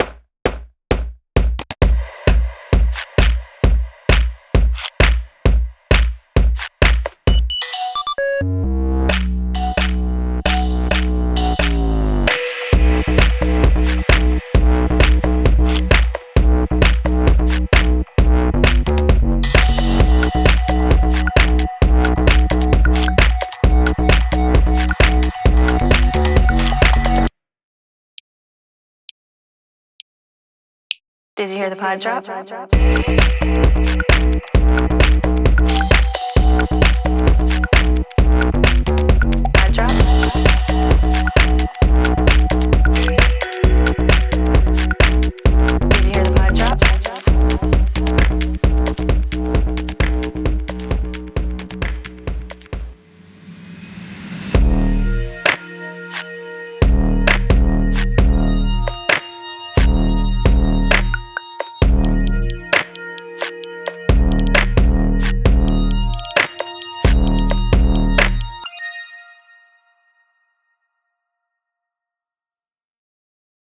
It’s more than just a catchy song you’ll want to dance to.